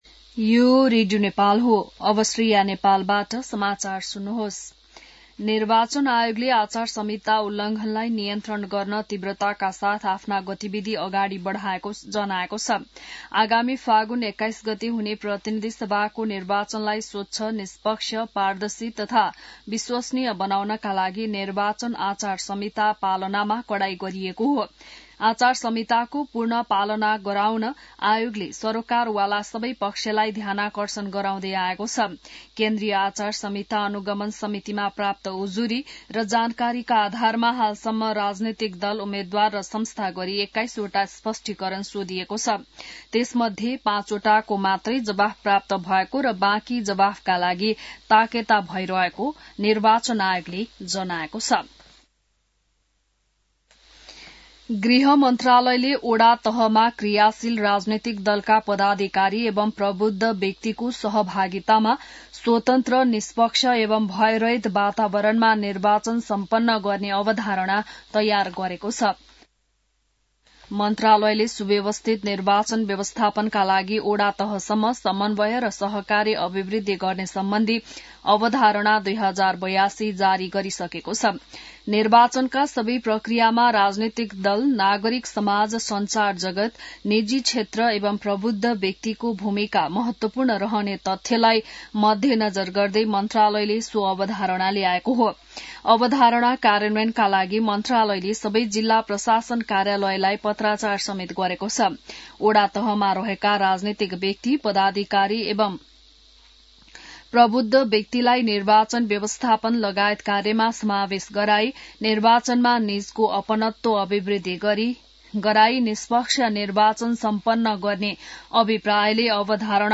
बिहान १० बजेको नेपाली समाचार : १७ माघ , २०८२